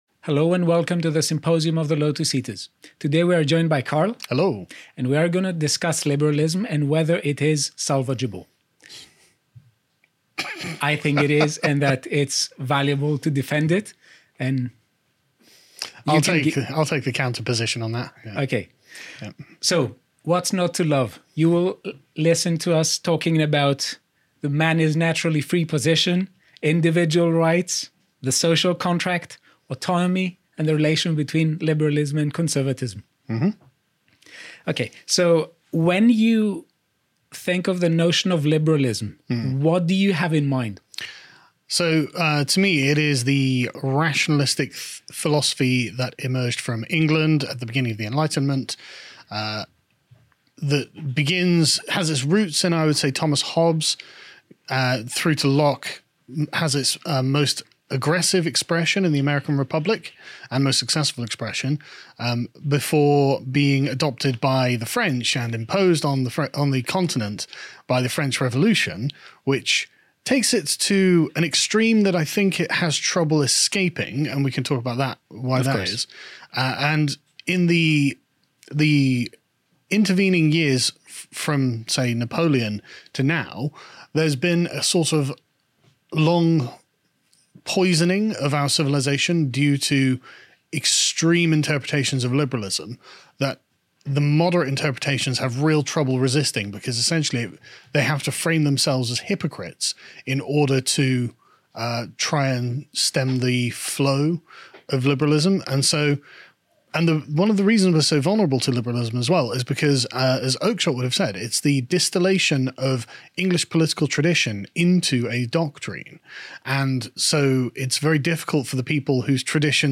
In this symposium, we debate whether classical liberalism can be saved or not. We touch upon issues such as: a) the notion of human natural freedom, b) the social contract tradition, c) autonomy, and d) whether liberalism and conservatism can be fused into a new paradigm.